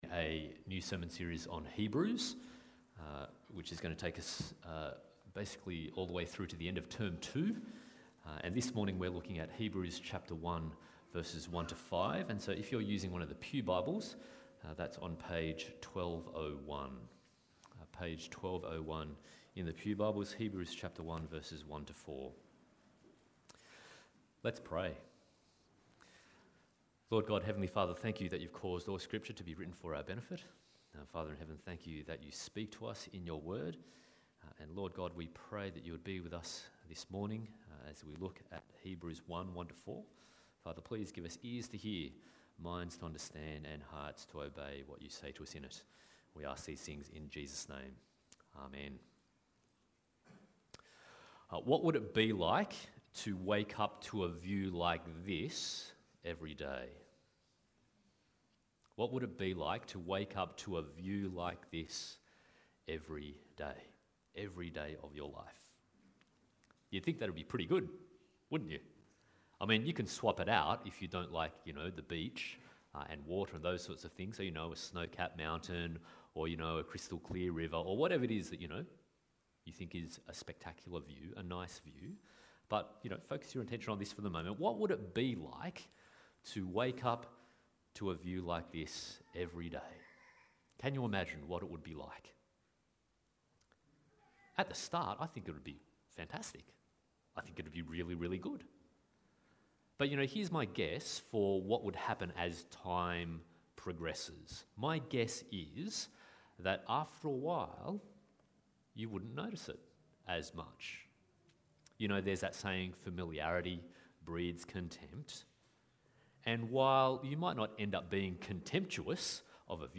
Hebrews Passage: Hebrews 1:1-4, Deuteronomy 5:22-27, Matthew 17:1-5 Service Type: Sunday Morning « I am the Resurrection and the Life Hebrews 1:5-2